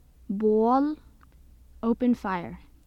An example of the vowel sound /o/ in Norwegian. This clip is taken from the UCLA Phonetics Lab Archive.